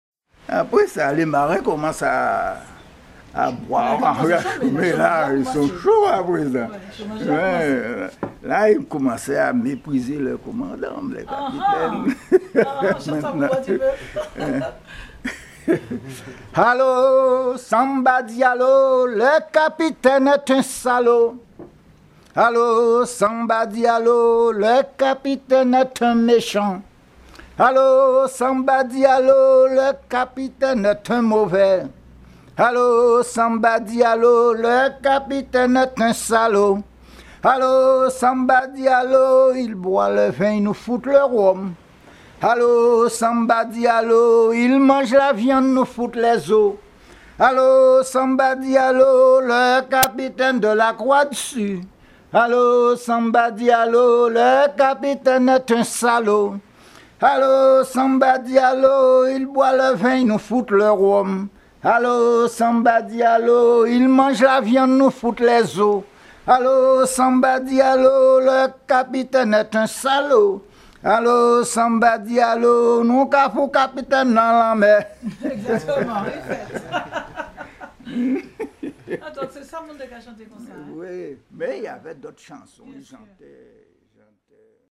chant de travail de la fête des marins, enregistré sur l'île Marie-Galante